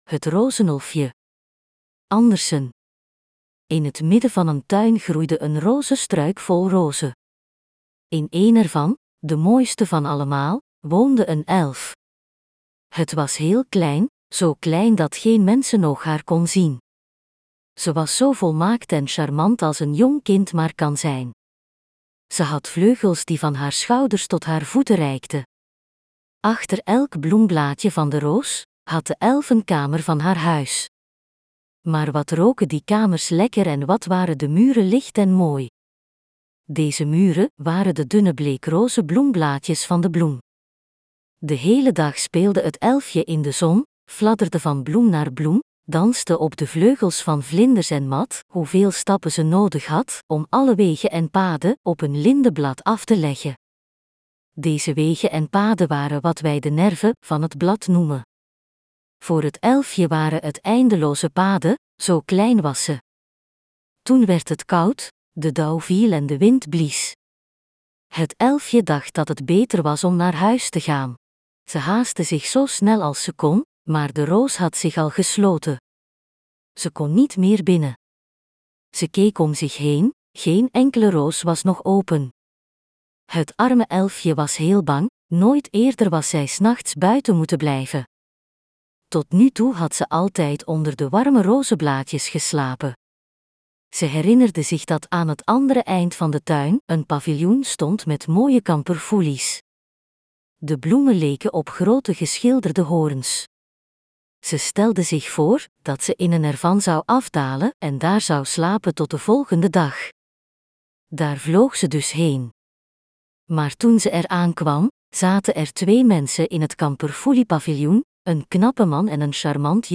Nederlandse versie